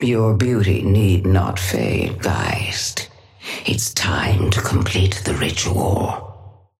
Sapphire Flame voice line - Your beauty need not fade, Geist. It's time to complete the ritual.
Patron_female_ally_ghost_start_04.mp3